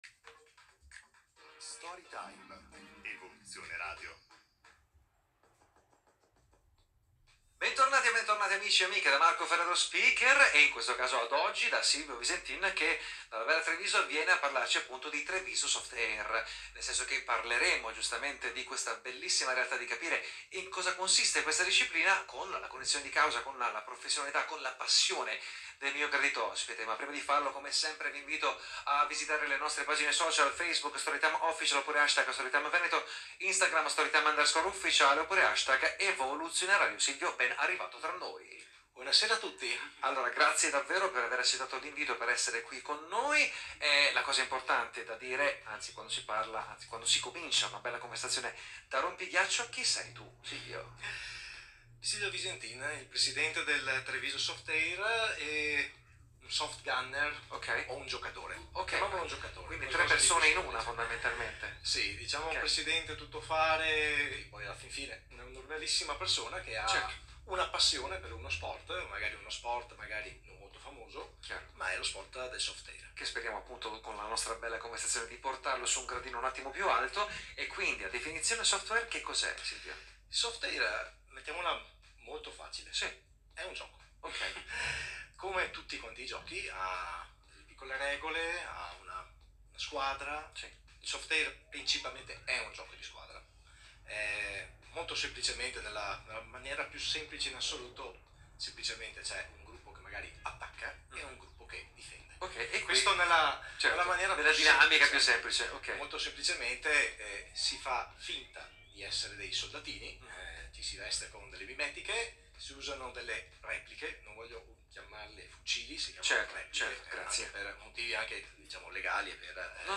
10-minuti di intervista radiofonica presso gli studi di Story Time, una rapida escursione attraverso le varie attività condotte dall'ASD Treviso Softair, tra allenamenti, amichevoli con altre ASD di softair, tornei competitivi, Mil-Sim di 24h...e lasertag!